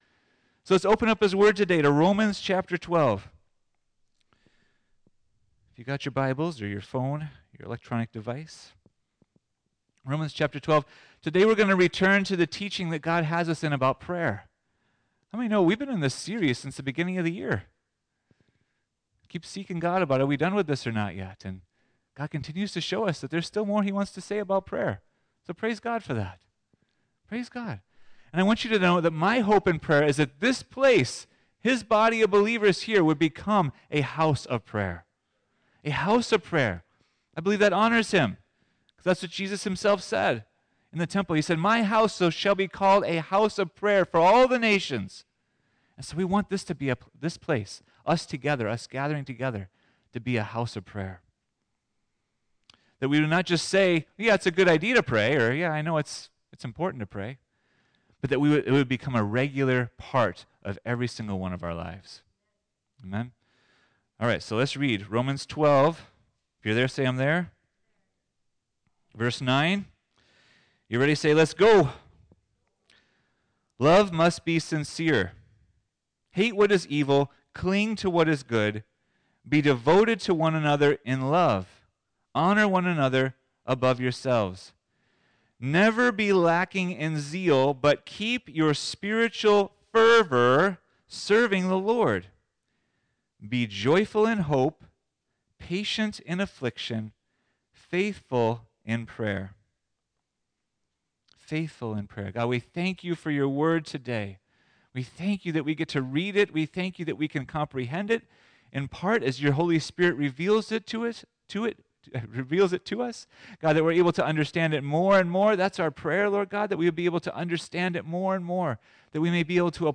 Teach Us To Pray – Faithful In Prayer – Friendship Church